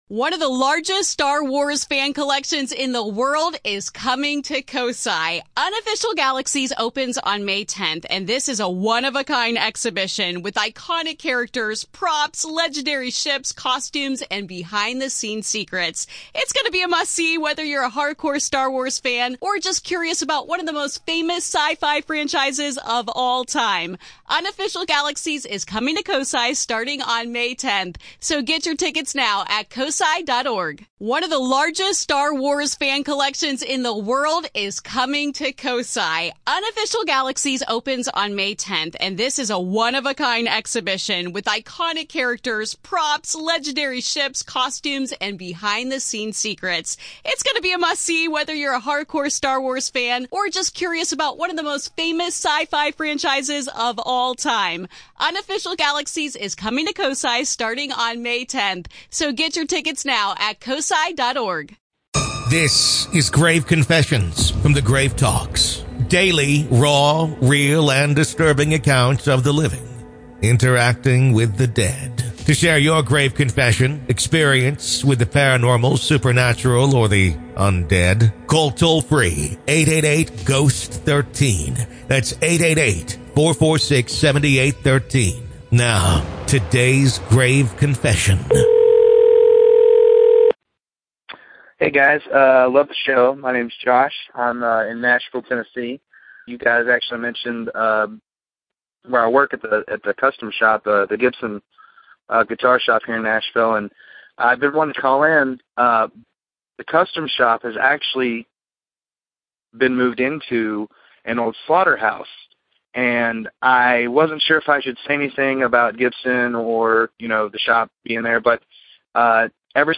Our caller shares the unsettling tale of working in a guitar custom shop that once doubled as a slaughterhouse. Strange nudges, mysterious malfunctions, and an eerie sense of always being watched set the stage for a haunting you won’t soon forget.